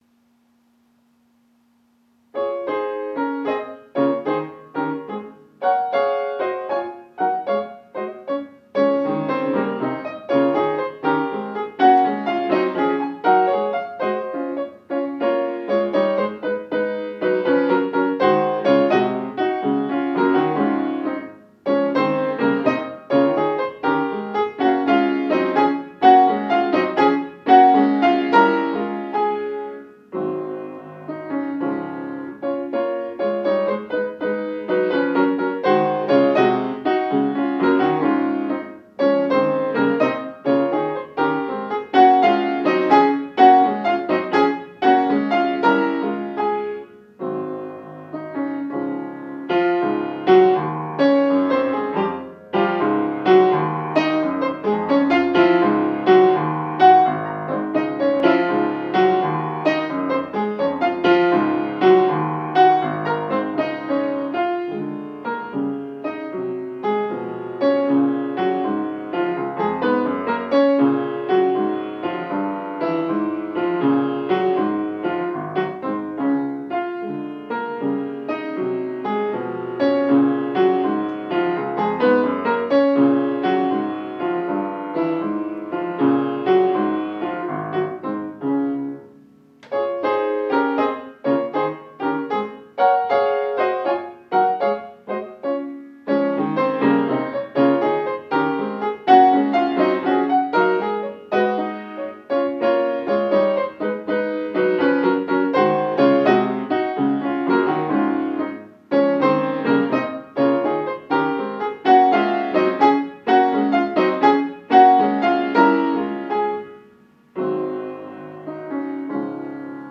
Plein de fautes et un arrêt (essai de son surtout).
1'36, 1,1 Mo, ogg (serviette devant micro)